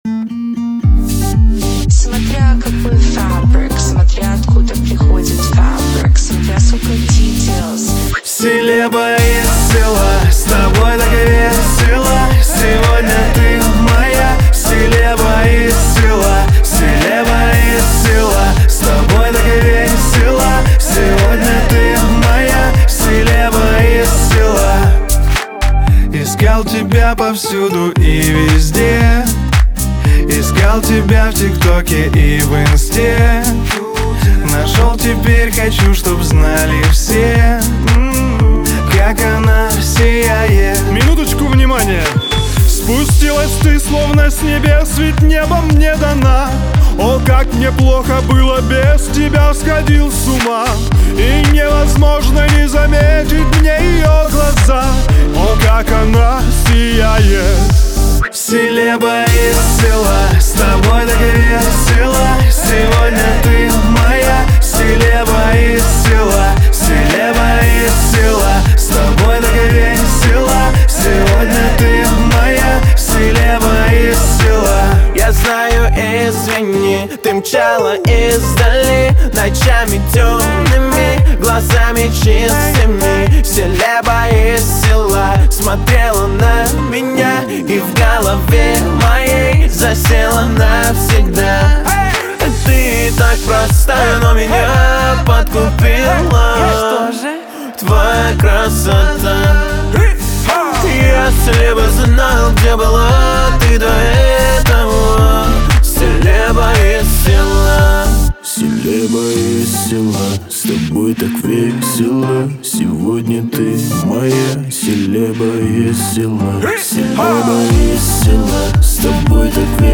Качество: 320 kbps, stereo
Русские поп песни